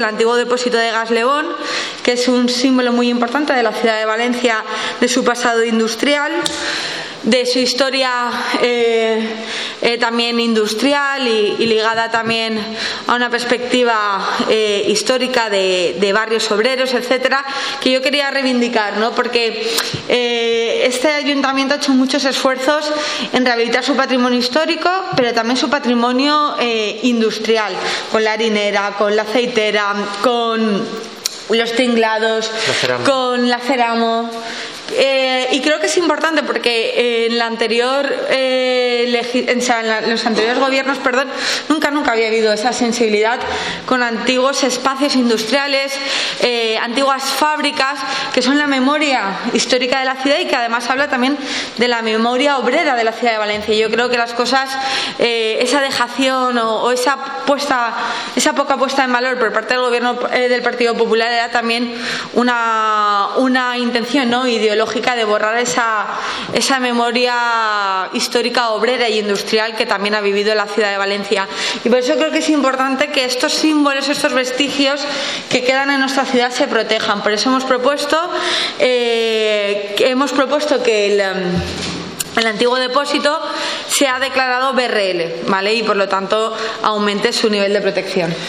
En la rueda de prensa posterior a la Junta de Gobierno, la vicealcaldesa, Sandra Gómez, acompañada del también vicealcalde, Sergi Campillo, ha explicado que el órgano colegiado ha aprobado la evaluación ambiental simplificada del PGOU para incorporar el Depósito de Gas Lebon al Catálogo Estructural de Espacios y Bienes Protegidos del Ayuntamiento como Bien de Relevancia Local.